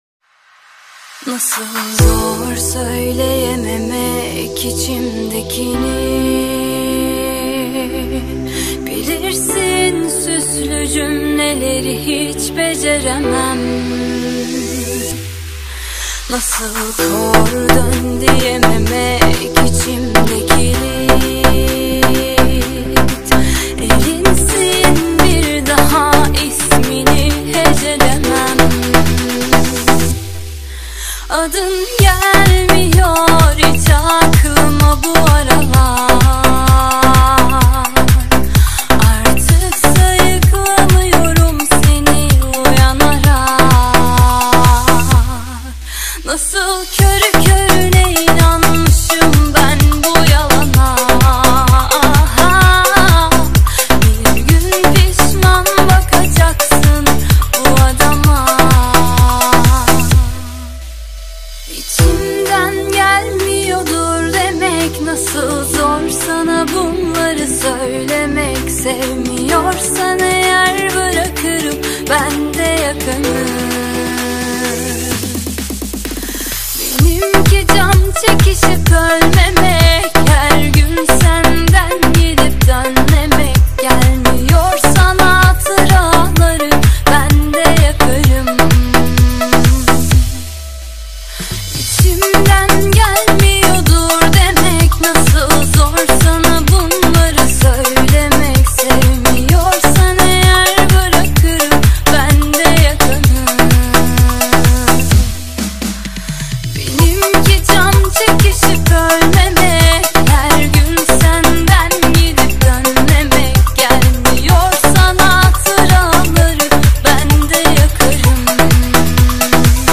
• Жанр: Турецкая музыка